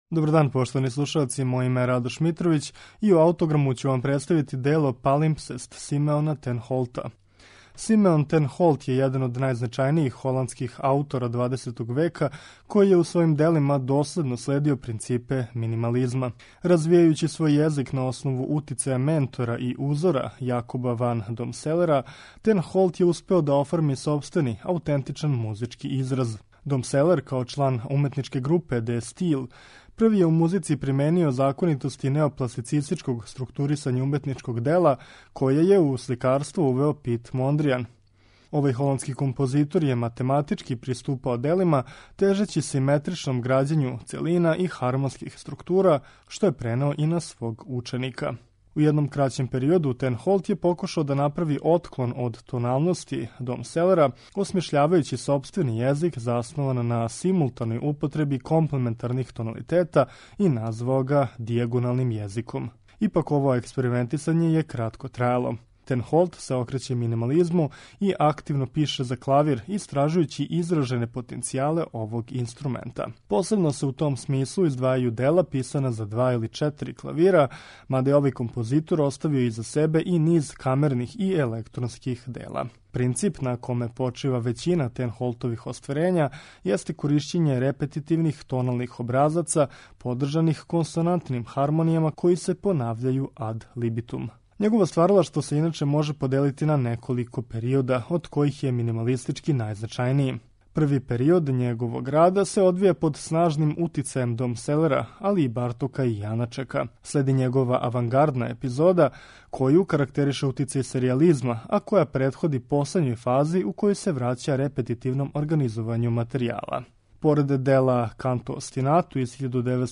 за гудачки септет
Репетитивност и романтичарски дух доминирају овим делом